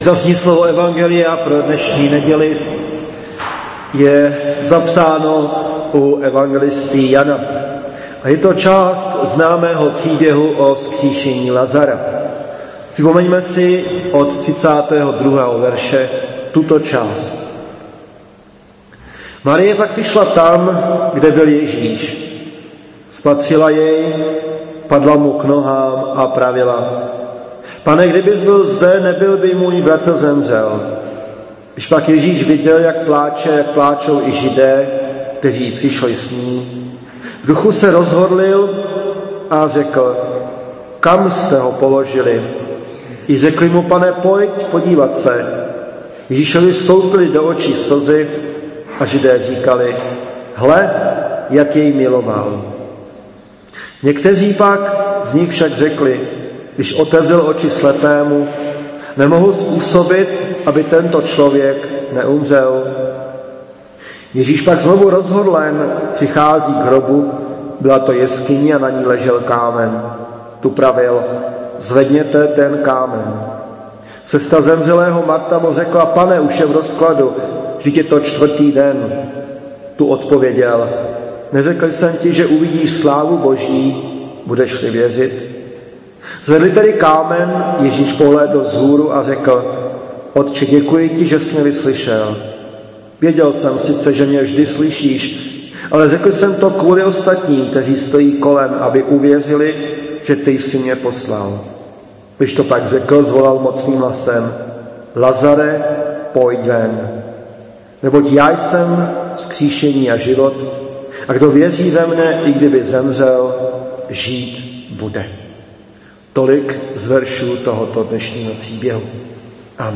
Zvukové záznamy kázání a bohoslužeb z evangelického kostela v Kloboukách u Brna ke stažení.
Kázání